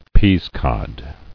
[pease·cod]